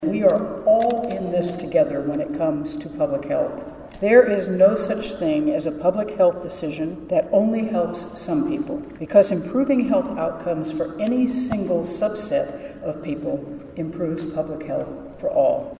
KS Gov. Kelly speaks to hundreds of health conference attendees
Kelly, speaking at the Manhattan Conference Center Wednesday, praised the efforts of those working in the public health field throughout the pandemic and said they were invaluable contributions for the health of all Kansans.